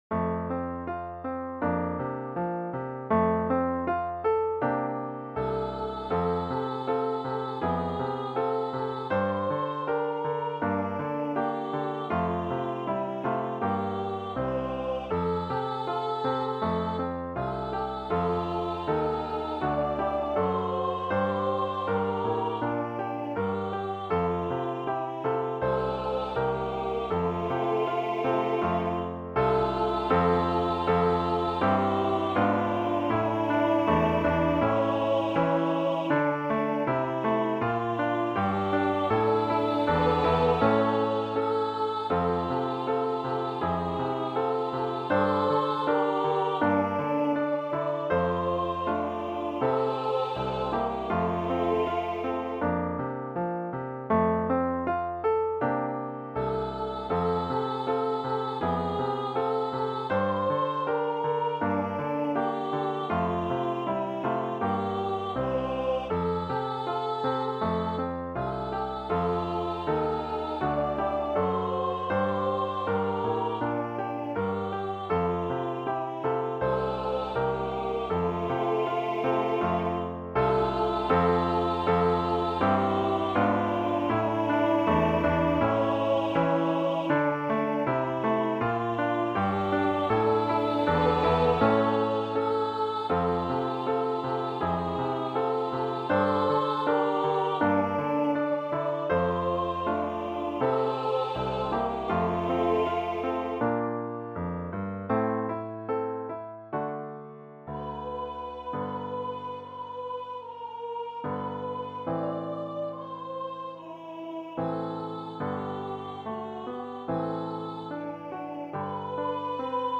Voicing/Instrumentation: 2 part choir